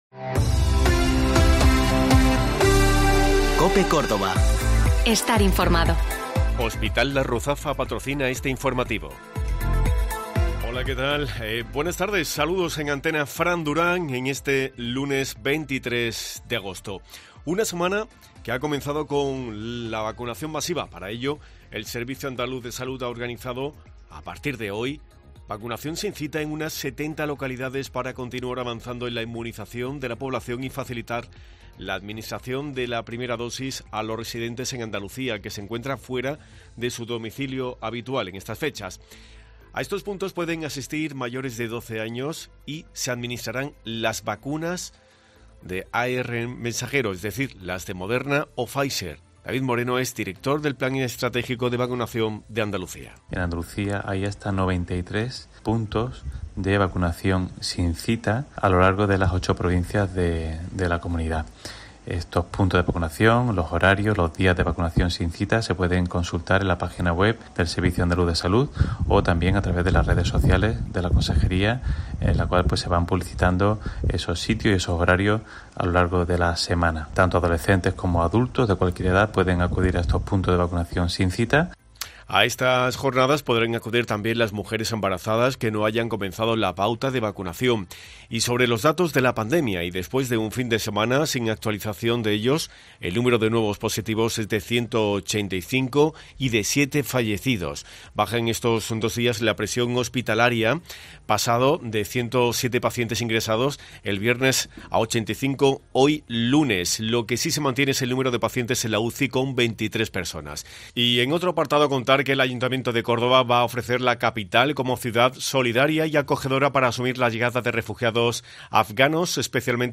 Así lo ha destacado el concejal en una rueda de prensa, en la que ha detallado que la actuación se llevará a cabo en la calle del Potro y ha resaltado que "esto es fruto del consenso y la negociación" entre el Consistorio, la Asociación de Casetas Tradicionales y el Consejo del Movimiento Ciudadano, todo ello tras salir a licitación por más de un millón de euros.
Salvador Fuentes, delegado de Urbanismo en el Ayuntamiento de Córdoba